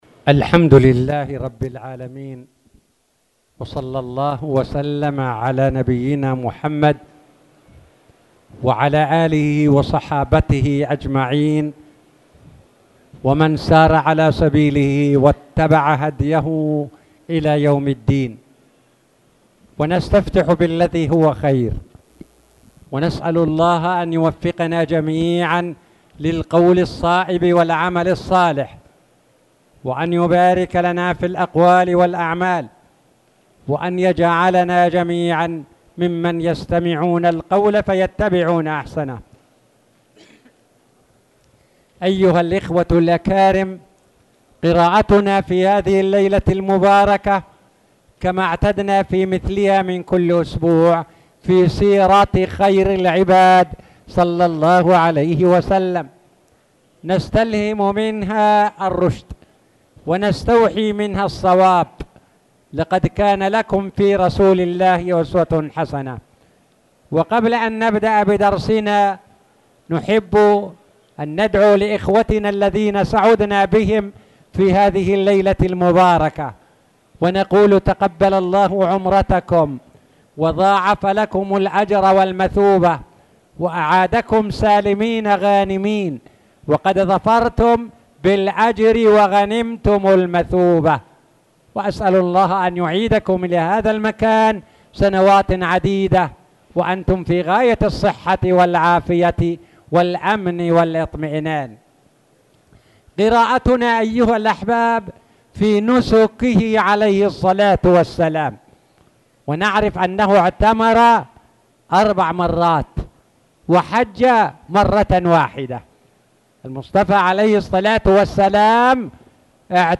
تاريخ النشر ٢٦ محرم ١٤٣٨ هـ المكان: المسجد الحرام الشيخ